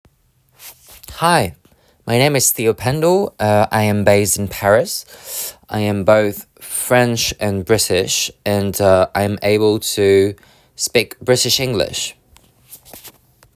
British English